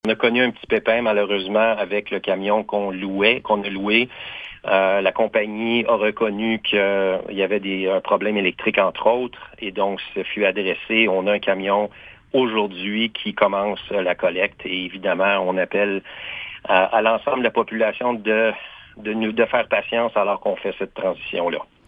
Le maire de Low, Patrick Beaudry, remercie la population pour sa patience et sa compréhension. Il explique le contexte particulier de ce retard :